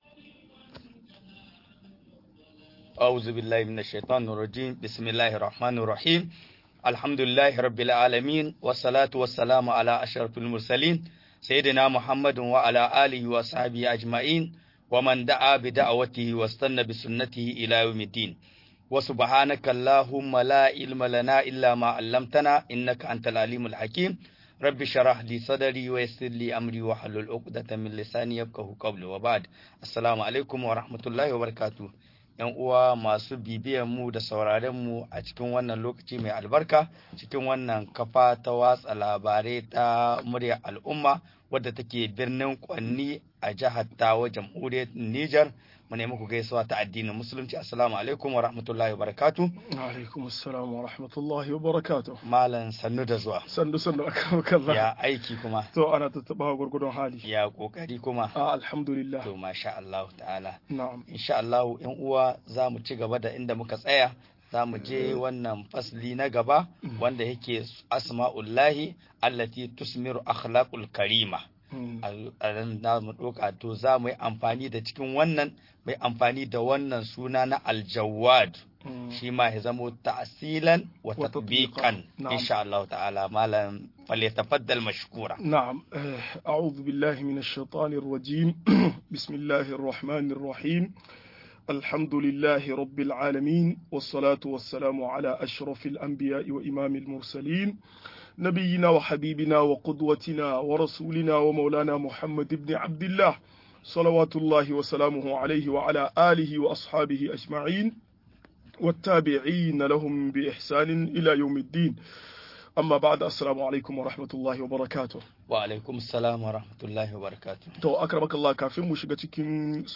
Sunayen Allah da siffofin sa-18 - MUHADARA